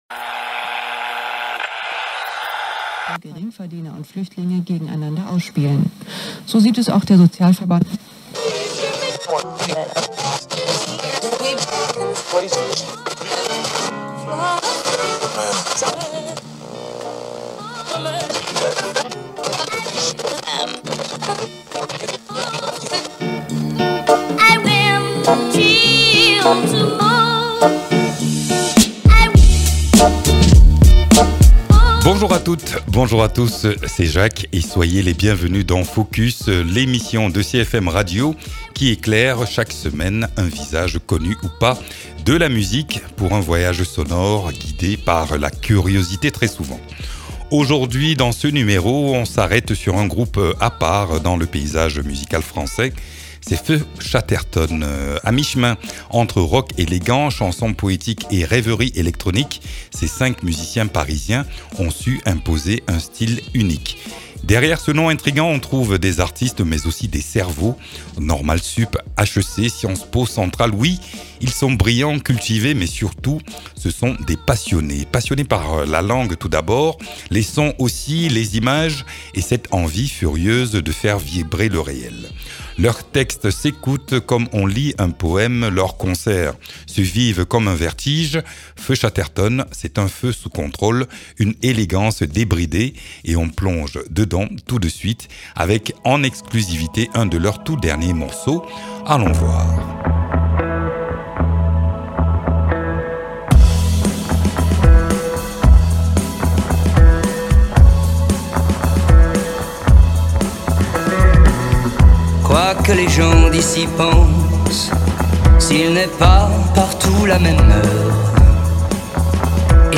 Pleins feux sur l’univers envoûtant d’un des groupes les plus captivants de la scène française contemporaine. Entre rock littéraire et échos électroniques, les cinq musiciens mêlent verbe incandescent et textures sonores audacieuses. Leur force ? Une écriture viscérale, portée par la voix habitée d’Arthur Teboul, et une musicalité qui refuse les frontières.